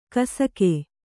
♪ kasake